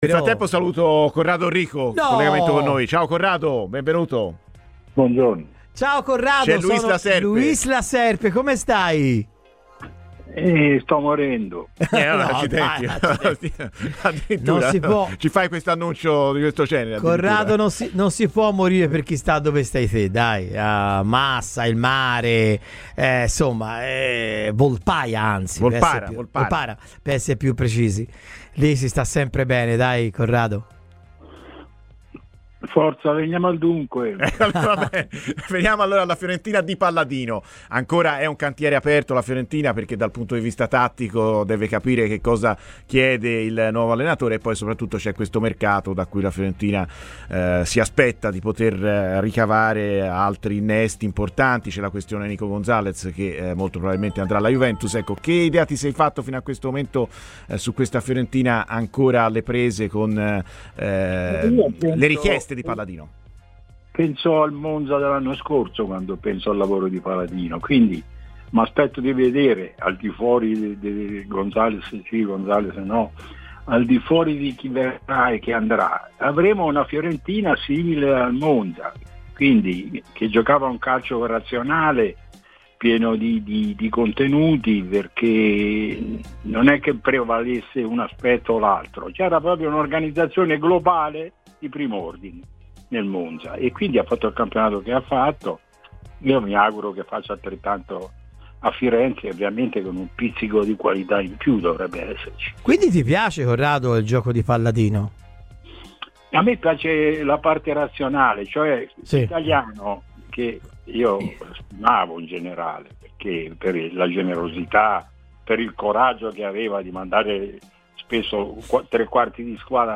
Ascolta il podcast per l'intervista completa. Corrado Orrico a Radio FirenzeViola